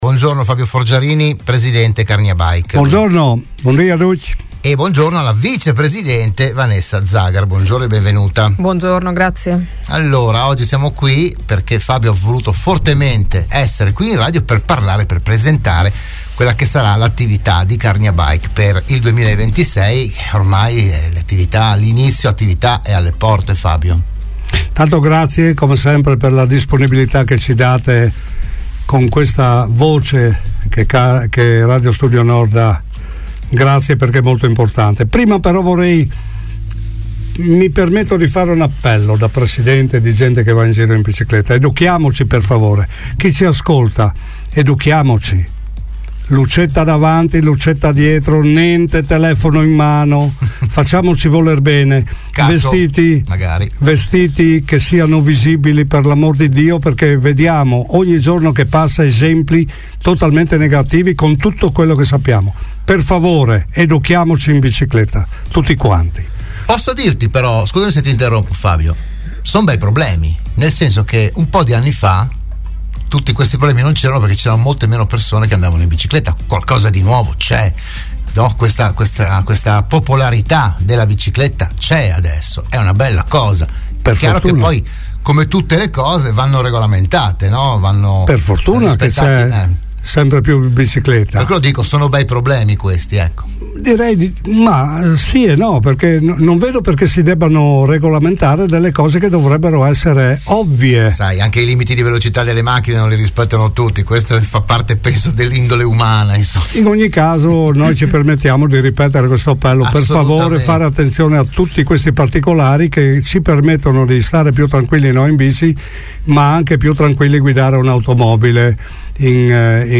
la trasmissione di Radio Studio Nord